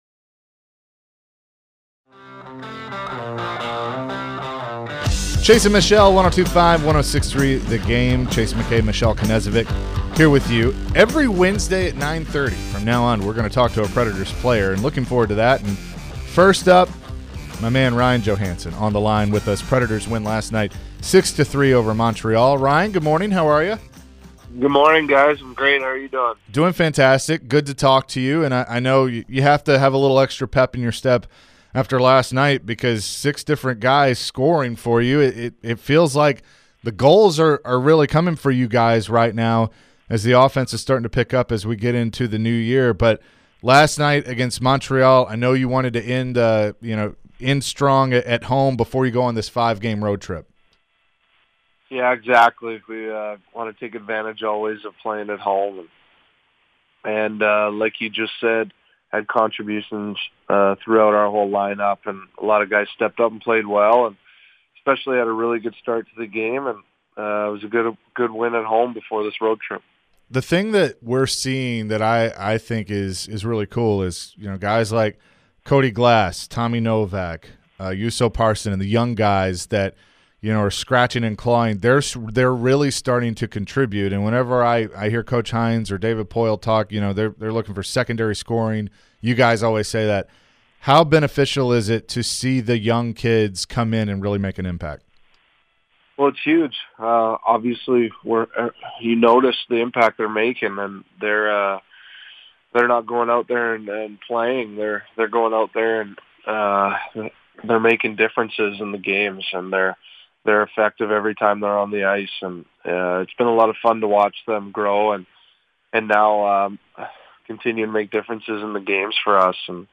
Ryan Johansen interview (1-4-23)